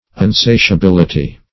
Search Result for " unsatiability" : The Collaborative International Dictionary of English v.0.48: Unsatiability \Un*sa`ti*a*bil"i*ty\, n. Quality of being unsatiable; insatiability.